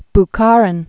(b-kärĭn, -är-), Nikolai Ivanovich 1888-1938.